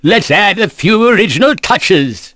Oddy-Vox_Halloween_Skill3.wav